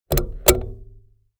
Lawn Mower, Switch On Or Off 2 Sound Effect Download | Gfx Sounds
Lawn-mower-switch-on-or-off-2.mp3